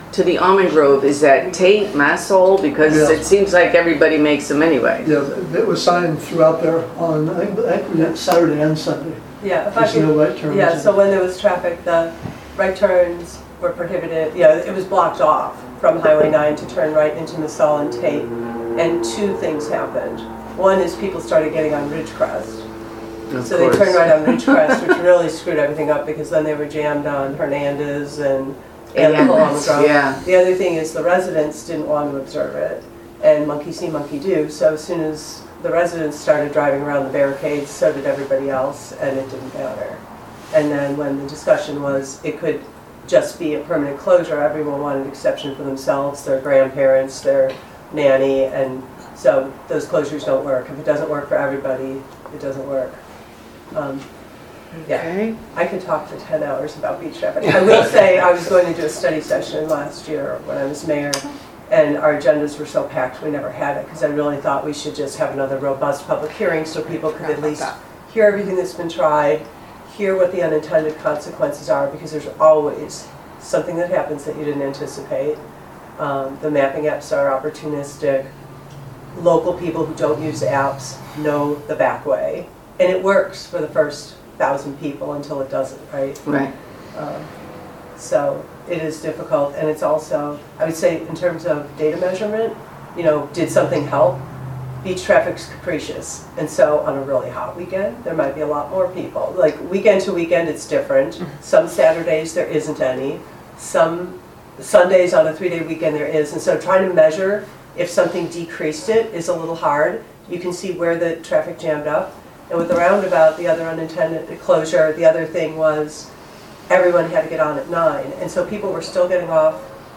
Audio Clip of Council member Ristow explaining Town's past mitigation efforts for beach traffic at February 8th, Complete Streets & Transportation Commission meeting